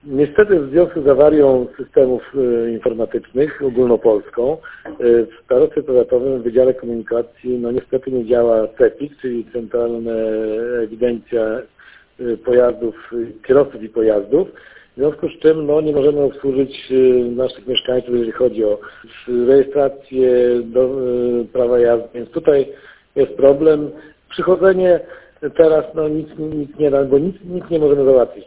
Marek Chojnowski – starosta powiatu ełckiego.